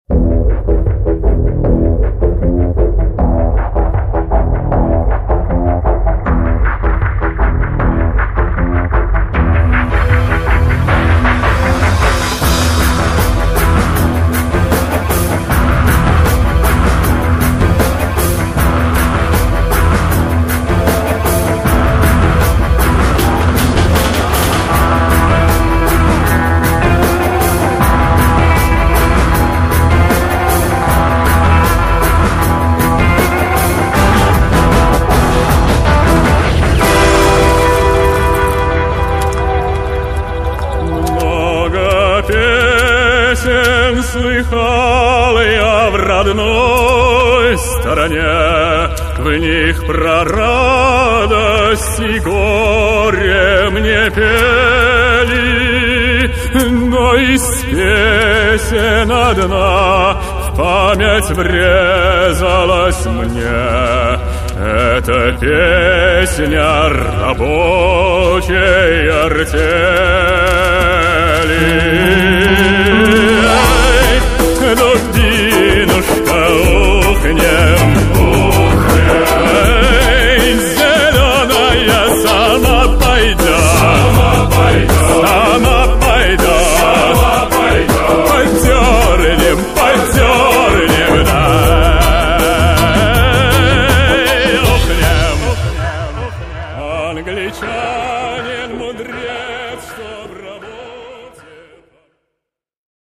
новый взгляд на аутентичное прошлое России в современном изложении